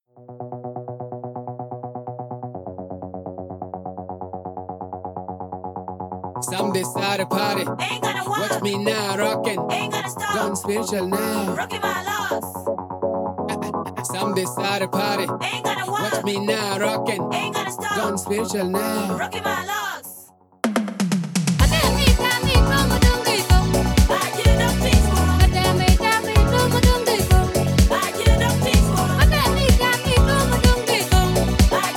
ジャンル(スタイル) DEEP HOUSE / AFRO HOUSE